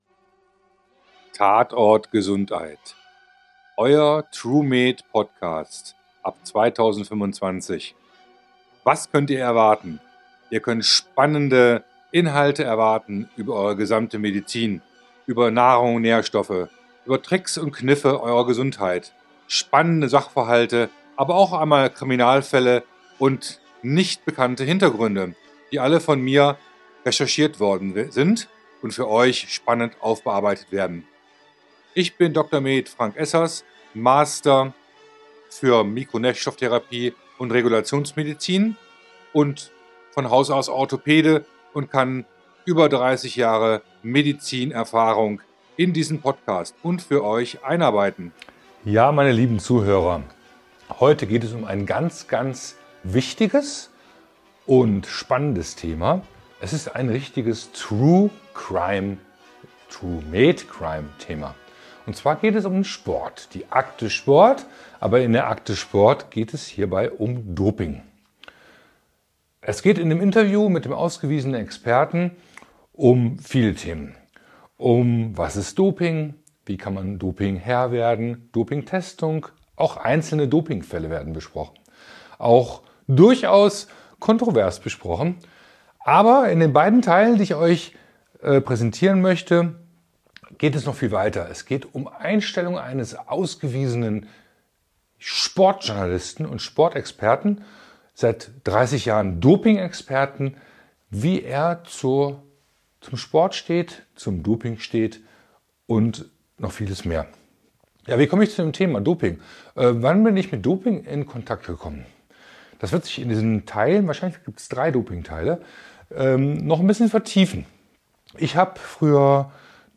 Vol.1, Der ARD-Experte Hajo Seppelt im Interview Teil 1, #67
Ich konnte als zentrale Basis ein Interview mit dem ARD-Experten Hajo Seppelt führen. Darum herum werde ich das Thema Doping aufarbeiten.